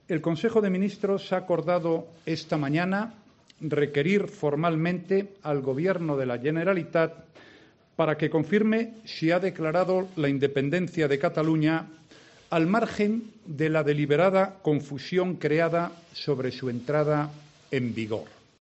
COMPARECENCIA DE RAJOY TRAS CONSEJO MINISTROS
En una declaración institucional en el Palacio de la Moncloa, tras la reunión extraordinaria del Consejo de Ministros que ha aprobado el requerimiento, Rajoy ha explicado que este requerimiento es previo a cualquiera de las medidas que el Gobierno puede adoptar al amparo del artículo 155.